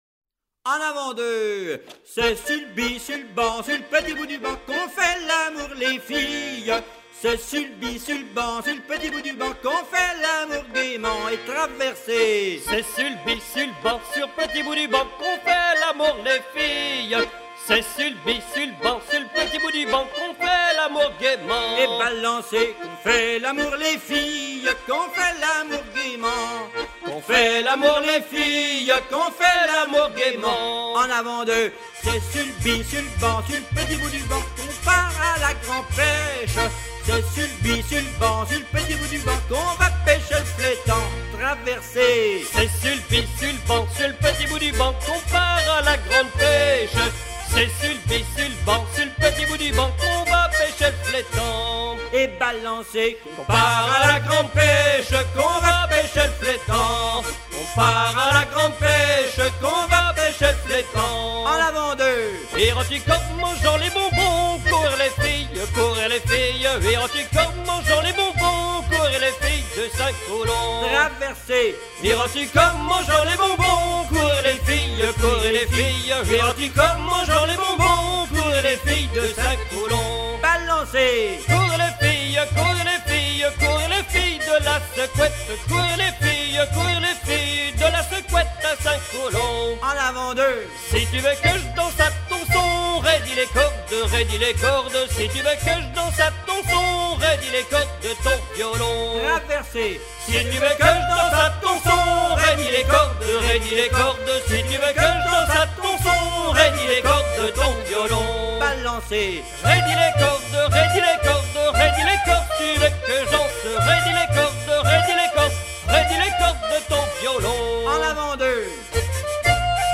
Couplets à danser
danse : branle : avant-deux ;
Pièce musicale éditée